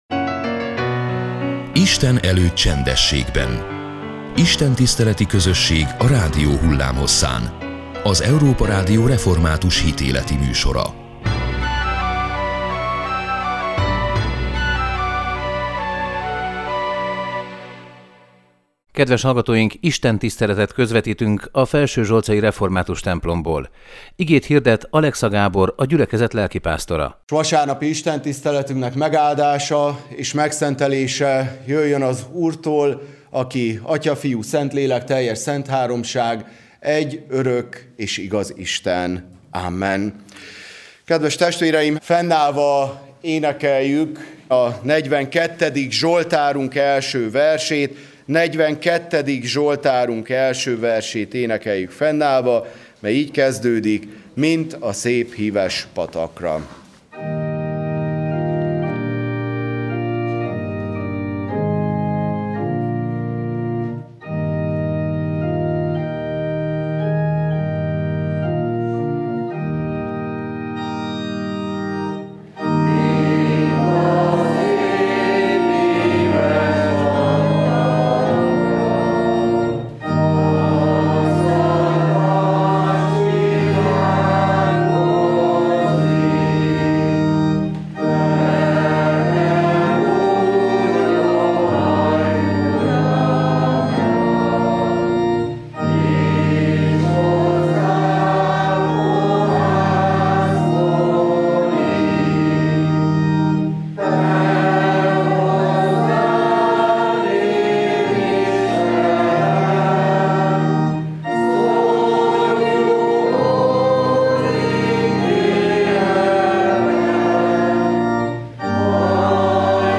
Istentiszteletet közvetítettünk a felsőzsolcai református templomból.